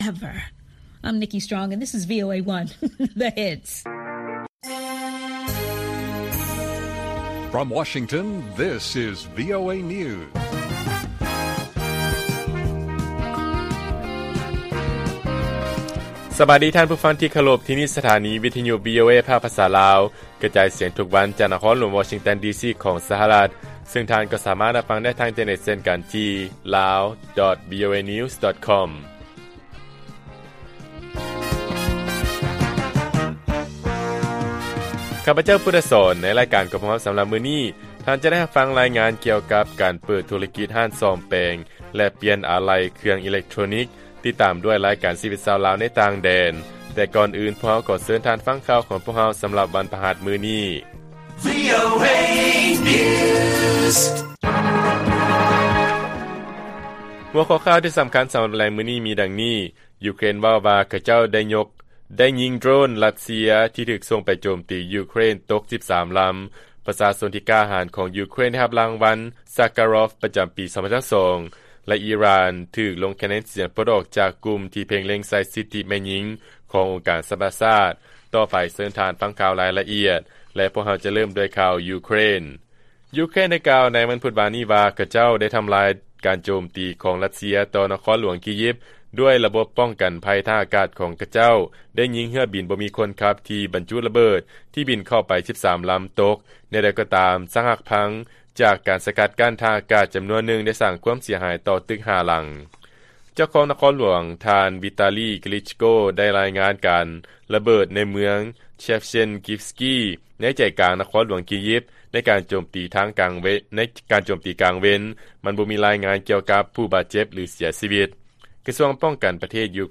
ລາຍການກະຈາຍສຽງຂອງວີໂອເອ ລາວ ວັນທີ 15 ທັນວາ 2022.